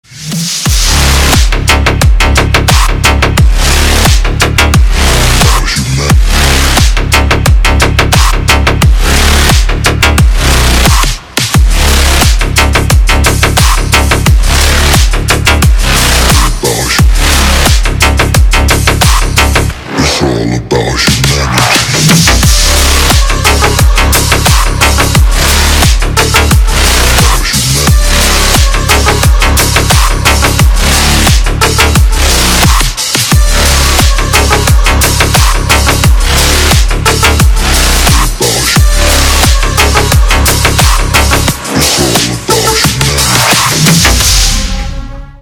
Громкие звонки, звучные рингтоны
Громкая нарезка на телефон